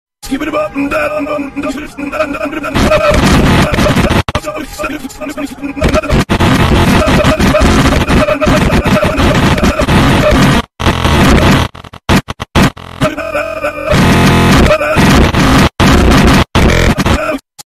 Skibidi Bop Mm Dada And Error Sound Effect Free Download